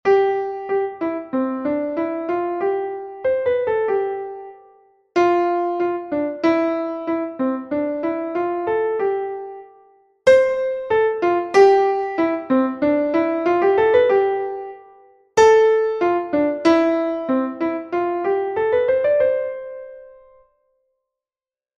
Exercise 4: major key
Now you have to work on consecutive notes (the scale), articulation, and accentuation (accent, staccato, slur), as well as dynamics.
melodic_reading_4_mayor.mp3